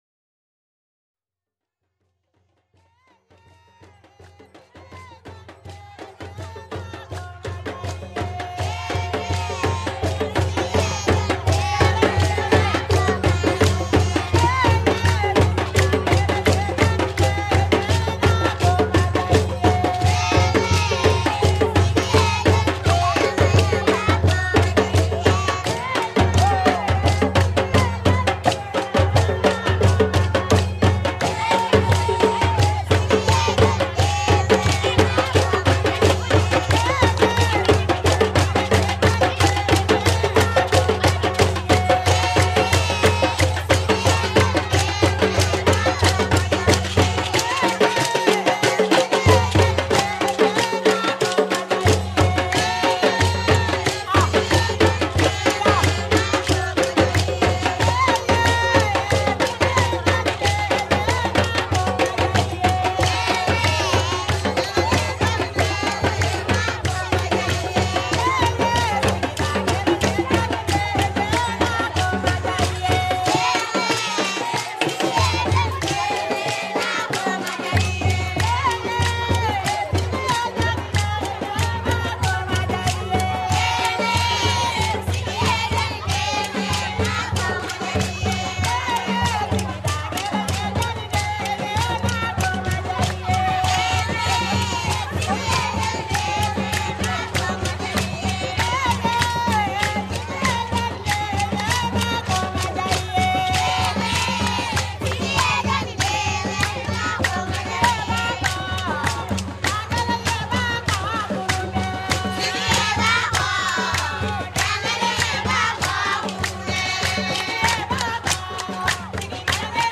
Rhythm to be heard and seen: a Bamana masquerade in Mali